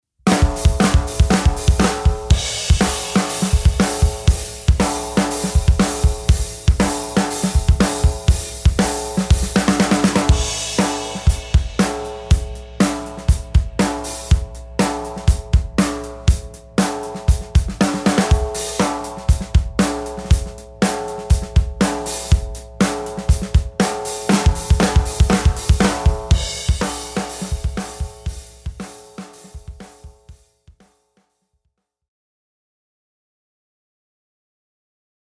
Masters-of-Maple-Black-Ugly-brass-hoop-snare.mp3